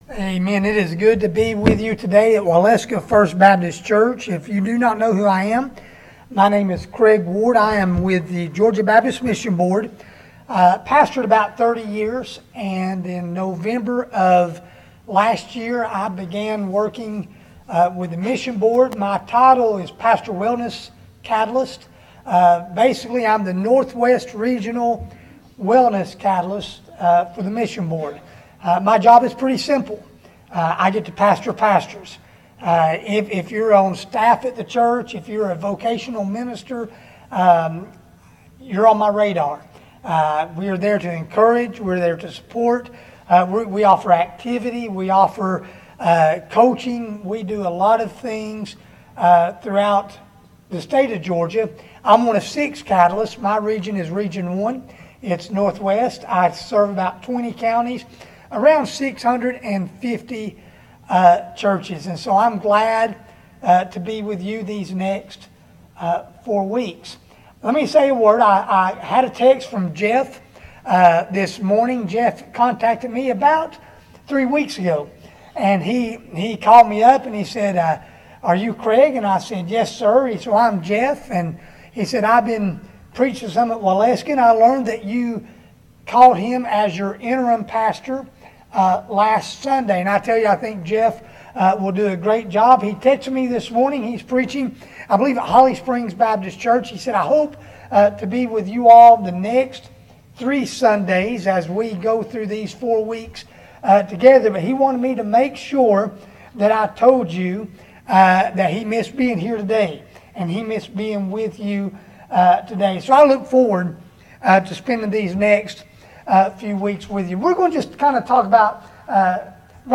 Sermons | Waleska First Baptist Church
Guest Speaker